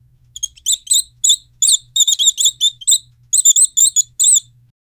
There is an old piano playing off key in the background 0:47 Created Oct 15, 2024 1:03 AM Rats squeaking, Rodents, Rats 0:05 Created Oct 22, 2024 10:40 AM
rats-squeaking-rodents-ra-snhqjxs6.wav